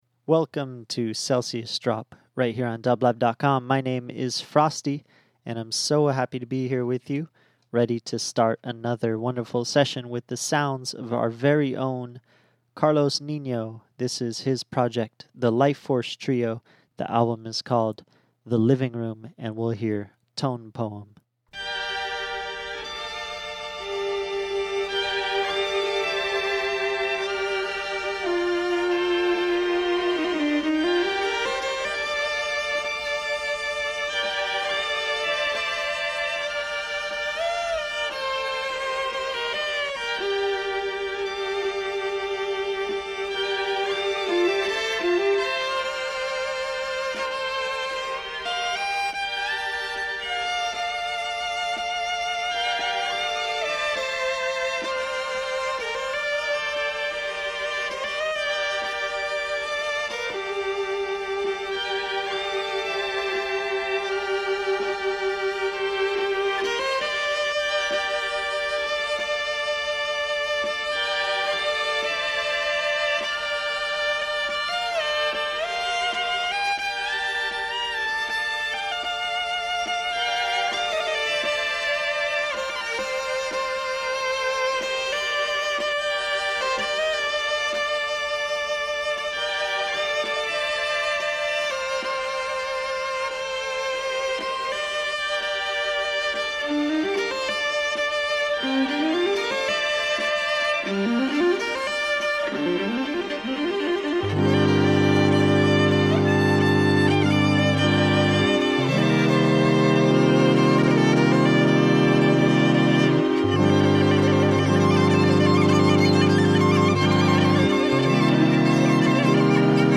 Electronic Indigenous International Rock